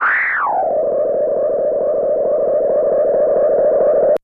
Filtered Feedback 01.wav